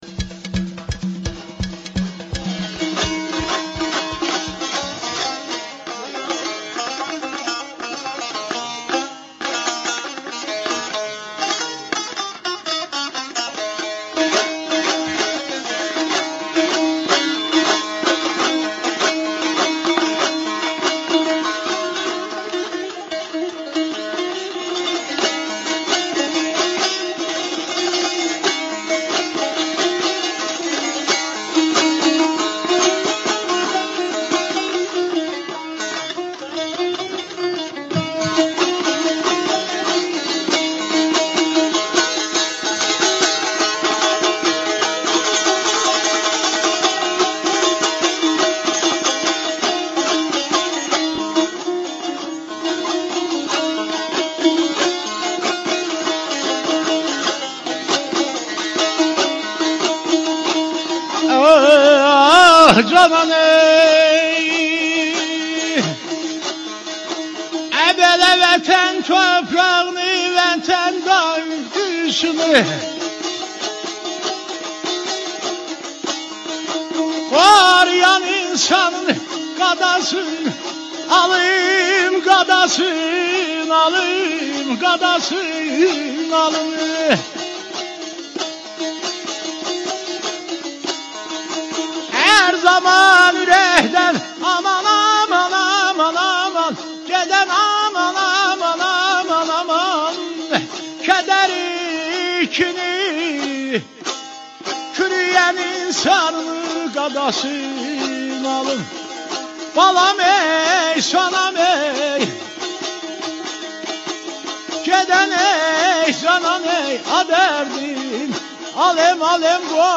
اجرای زنده عاشیق روی آنتن صبا
رادیو صبا با حضور در شانزدهمین جشنواره موسیقی نواحی همراه هنرمندان و نوازندگان موسیقی نواحی شد.
«صباهنگ» از شنبه تا دوشنبه ساعت 18:30 از محل برگزای این جشنواره در تالار رودكی پخش می شود.